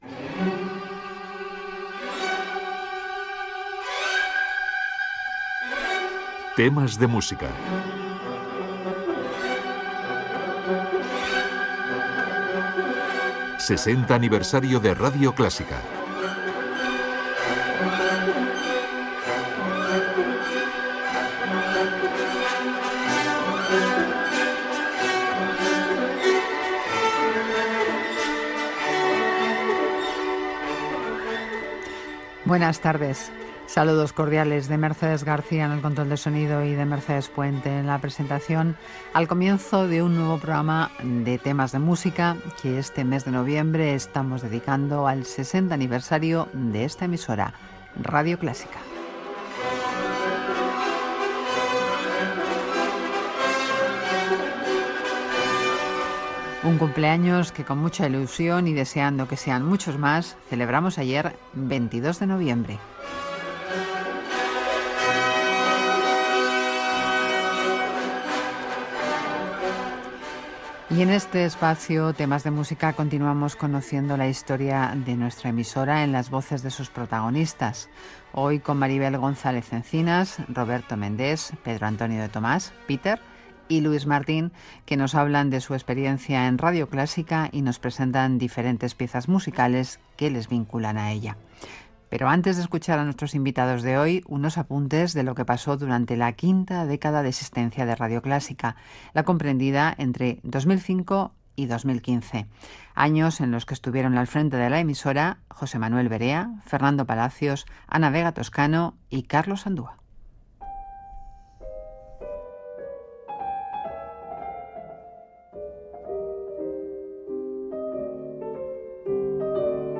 Careta del programa, espai dedicat als 60 anys de Radio Clásica, la dècada de 2005 a 2015
Gènere radiofònic Musical